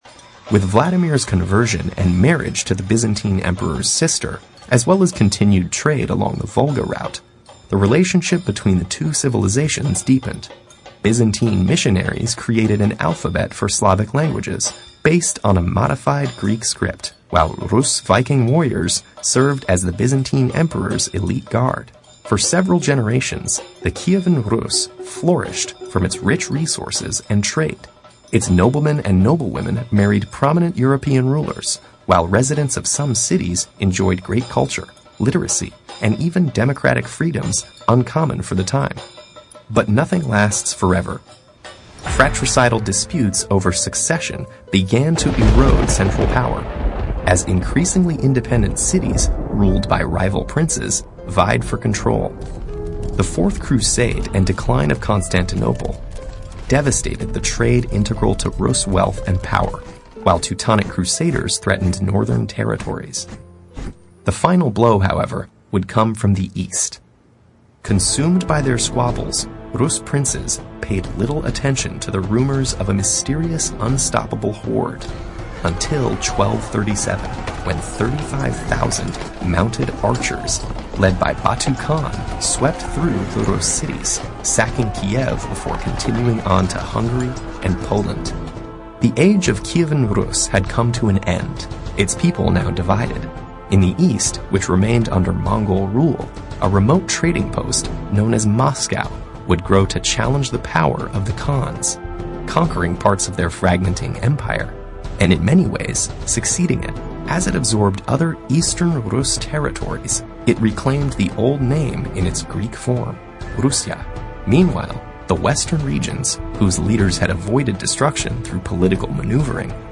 TED演讲:俄罗斯的起源(2) 听力文件下载—在线英语听力室